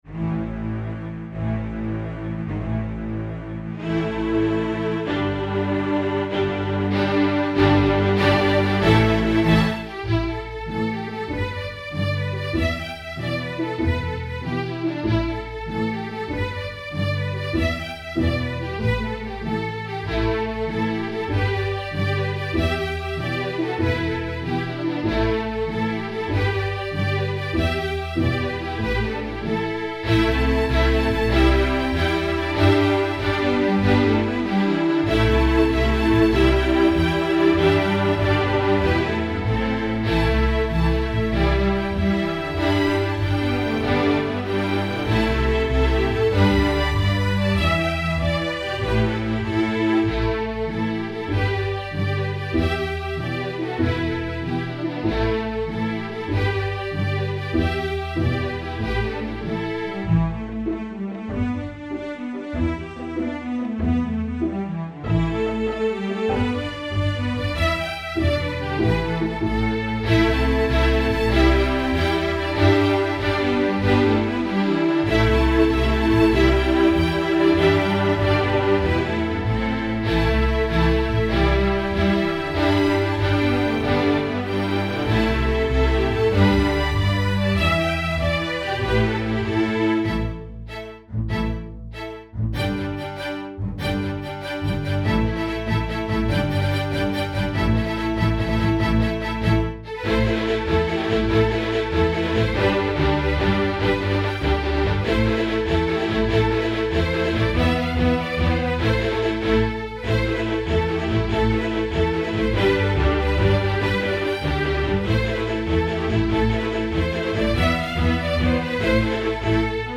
INTERMEDIATE, STRING QUARTET
Notes: double stops, pizz, spiccato, ricochet
cello, 4th position
Key: E minor/ A minor